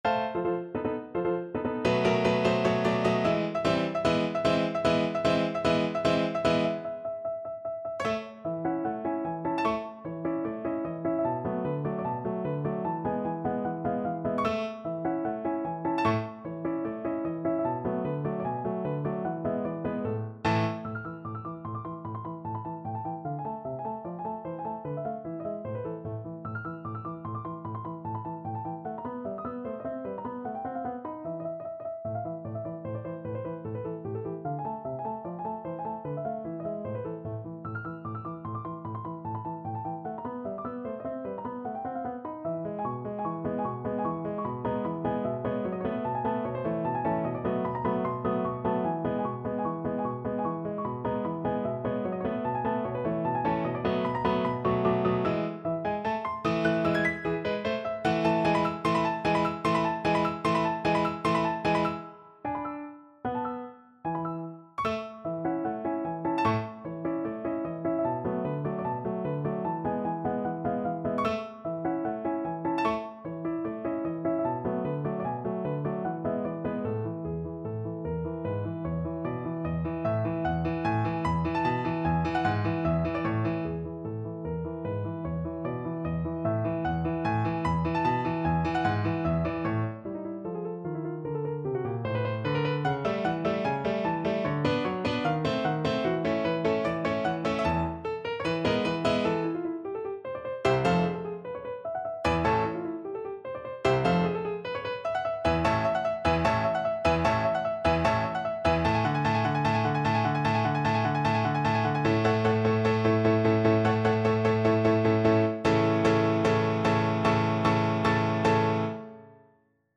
Piano version
No parts available for this pieces as it is for solo piano.
2/4 (View more 2/4 Music)
Allegro vivacissimo ~ = 150 (View more music marked Allegro)
Piano  (View more Intermediate Piano Music)
Classical (View more Classical Piano Music)